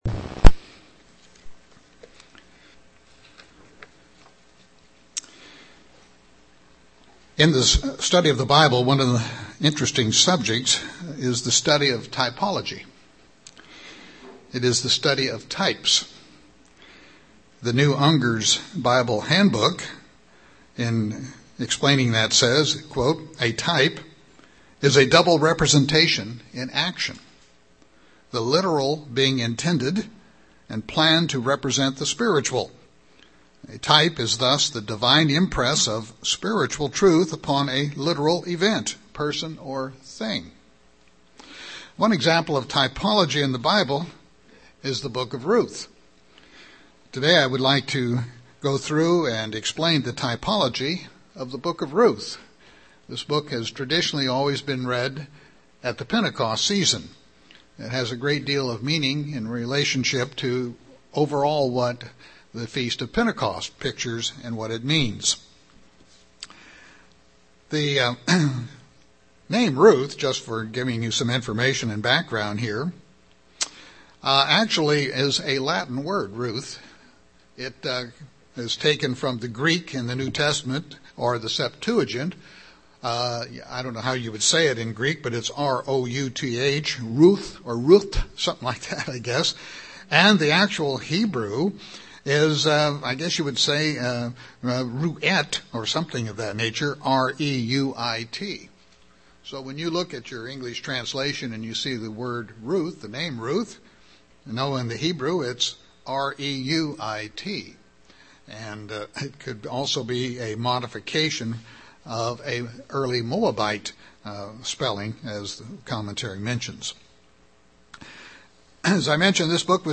5-26-12 Sermon.mp3